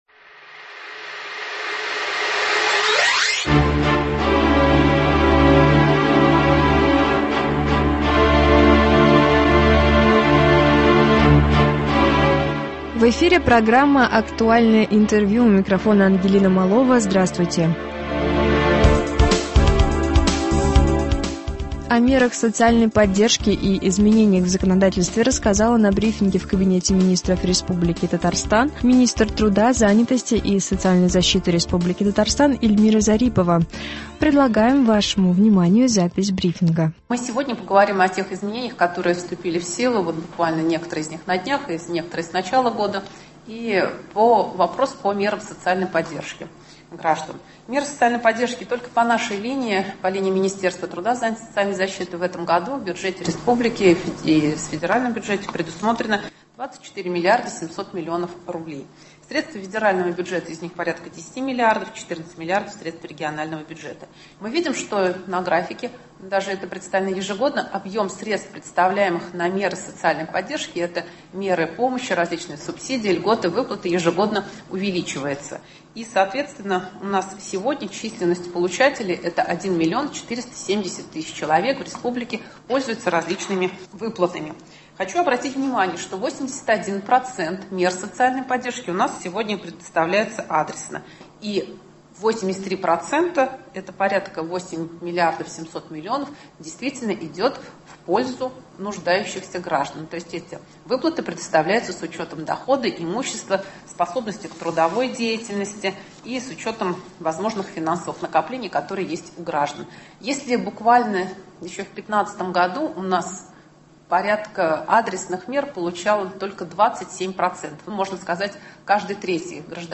О мерах социальной поддержки и изменениях в законодательстве рассказала на брифинге в КМ РТ Министр труда, занятости и социальной защиты Республики Татарстан Эльмира Зарипова.